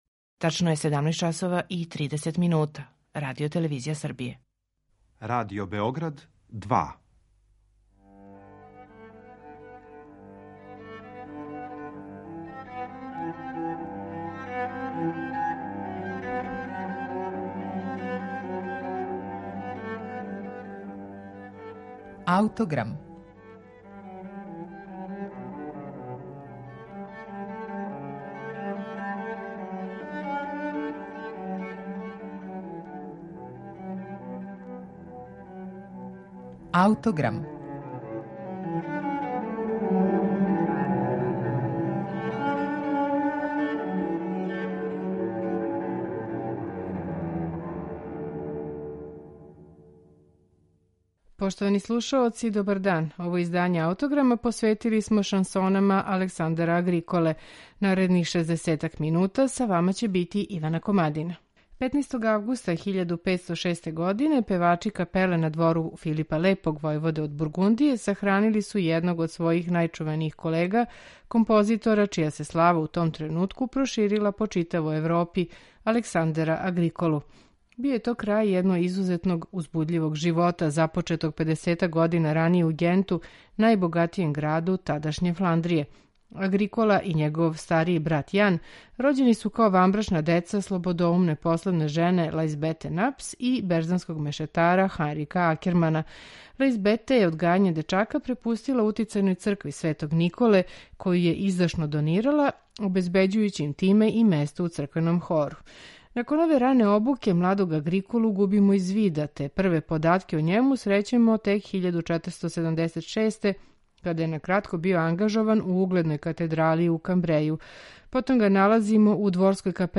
Данашњи Аутограм посветили смо Агриколиним шансонама, које су настајале као обраде популарних мелодија његовог времена. Слушаћете их у интерпретацији контратенора Мајкла Ченса и ансамбла виола Fretwork .